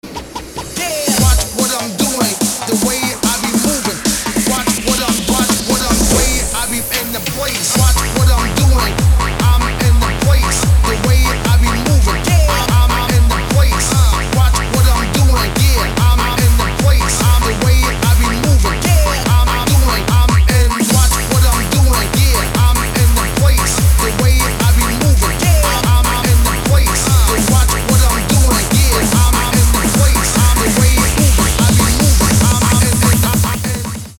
• Качество: 320, Stereo
мужской голос
ритмичные
dance
Electronic
электронная музыка
энергичные
динамичные
Hard dance
Rave